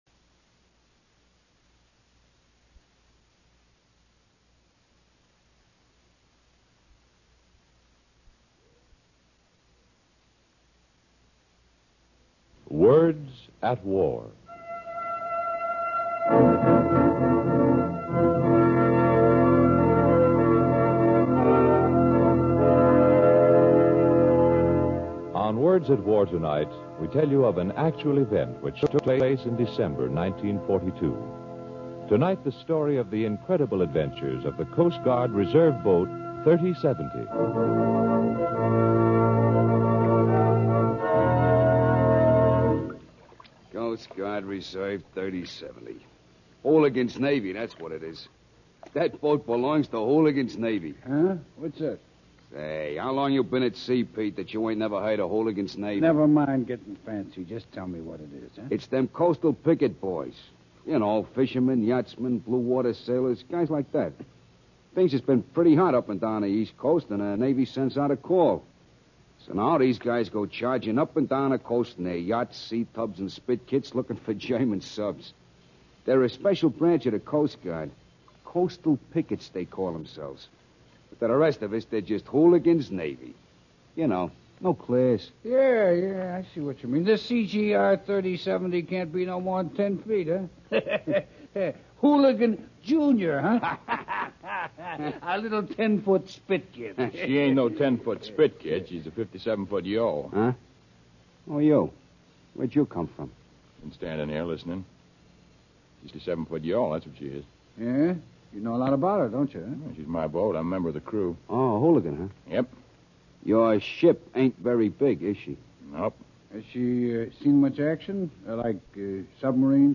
Words At War, the series that brings you radio versions of the leading war book another adaptation of an important war book, “The Navy Hunts the CGR-3070”. A small yawl on submarine patrol for the Navy gets caught in a storm and is swept out to sea.